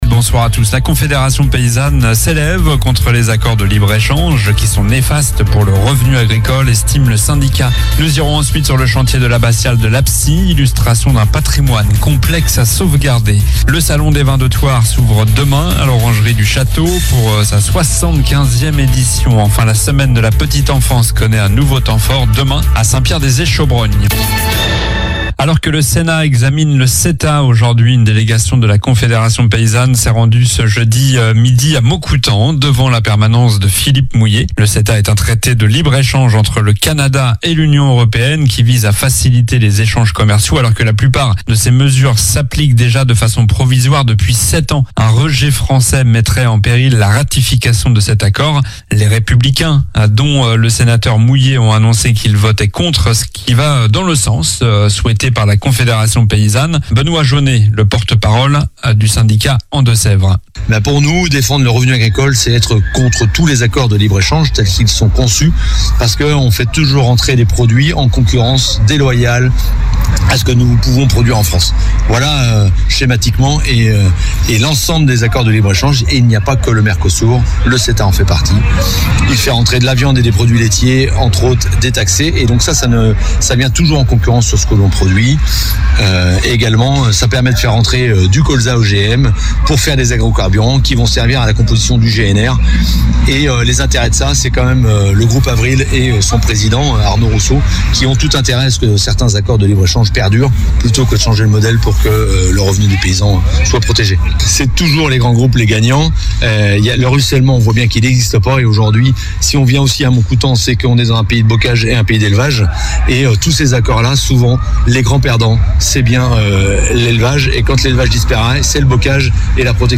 Journal du Jeudi 21 mars (soir)